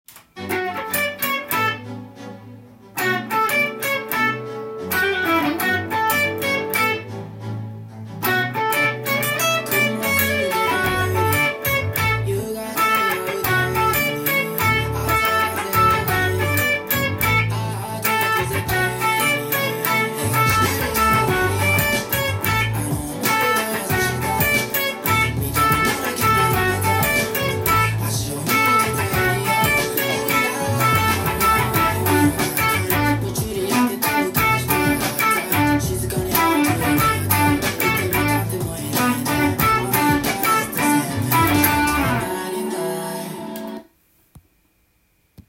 カッティングTAB譜
音源に合わせて譜面通り弾いてみました
使用されているスケールがDメジャースケールが主になり
イントロがカッティング奏法を使用して弾いていきます。